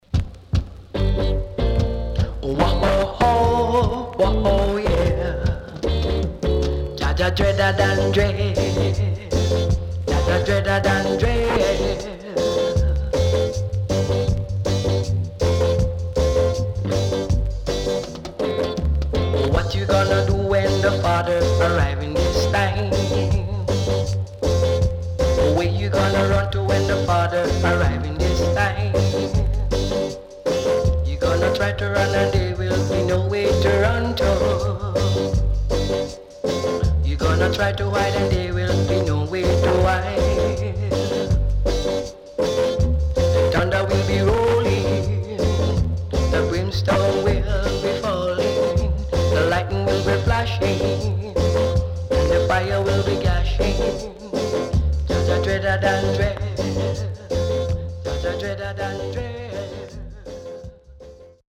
Good Funky Reggae & Dubwise